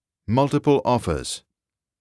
[muhl-tuh-puh l] [aw-fers]